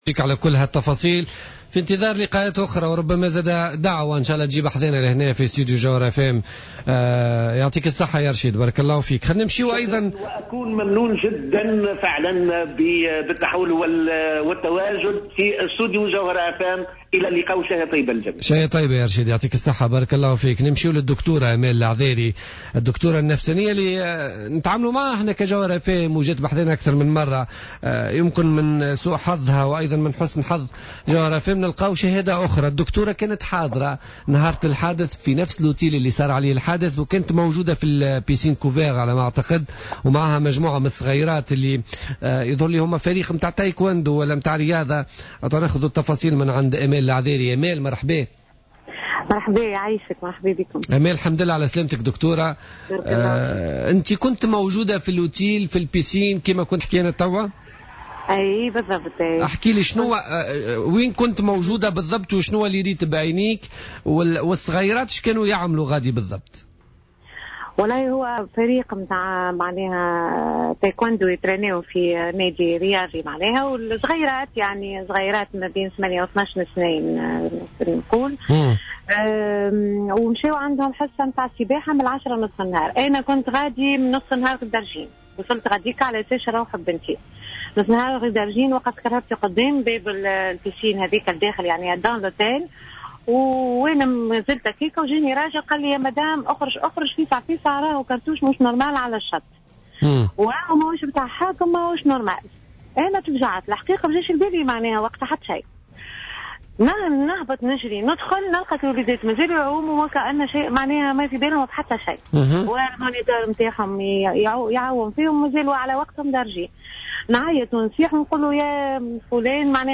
شهادة جديدة من موقع الهجوم الإرهابي على نزل سوسة